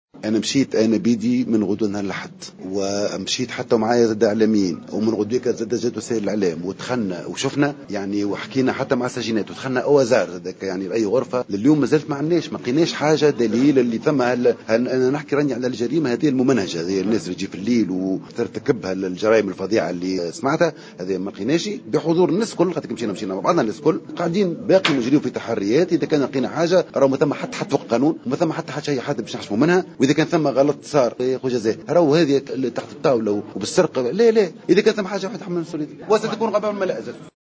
وأضاف الوزير في تصريح لمراسل "الجوهرة أف أم" اليوم على هامش توقيع اتفاقية إطارية بين وزارتي الثقافة والعدل لتخصيص برامج ثقافية وعروض فنية لفائدة المساجين أن التحريات متواصلة في الغرض، مشيرا إلى أنه لا أحد فوق القانون إذا ثبت تورط عدد من موظفات السجن في التعذيب، وفق تعبيره.